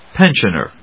音節pen・sion・er 発音記号・読み方
/pénʃ(ə)(米国英語), pénʃ(ə)(英国英語)/